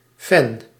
Ääntäminen
Synonyymit bewonderaar aanhanger supporter ventilator Ääntäminen Tuntematon aksentti: IPA: /fɛn/ IPA: /fɑn/ Haettu sana löytyi näillä lähdekielillä: hollanti Käännös Substantiivit 1. fan 2. addict Suku: m .